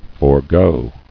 [for·go]